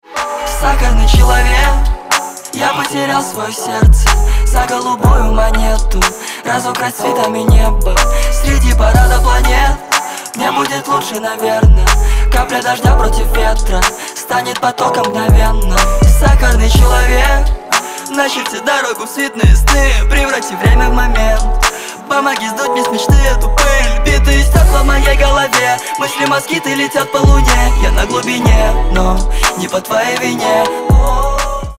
• Качество: 264, Stereo
русский рэп
Bass